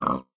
sounds / mob / pig / say3.ogg